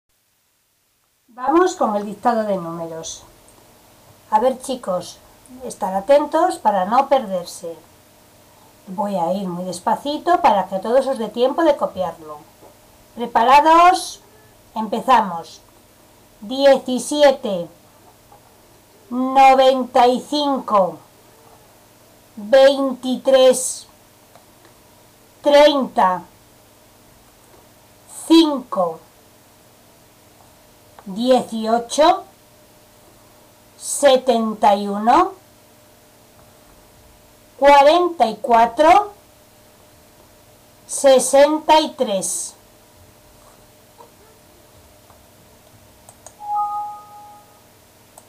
Dictado de números pág. 165
Dictado_de_numeros_Pag_165.mp3